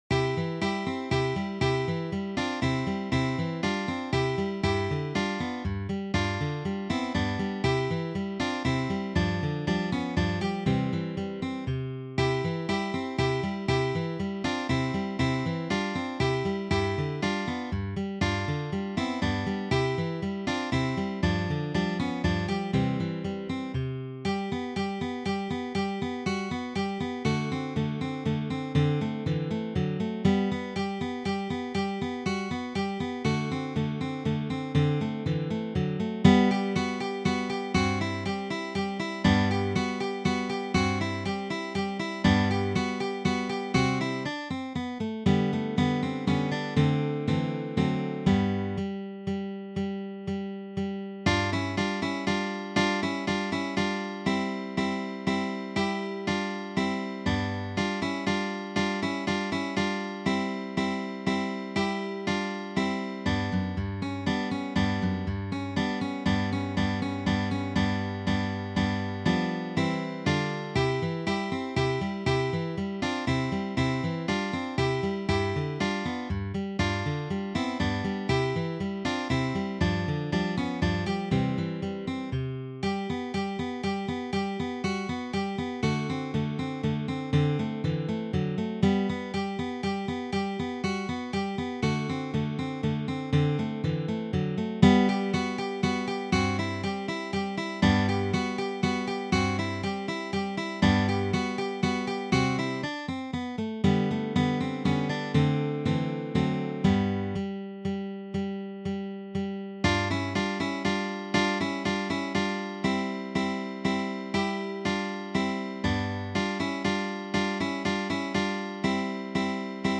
Intermediate arrangement for three guitars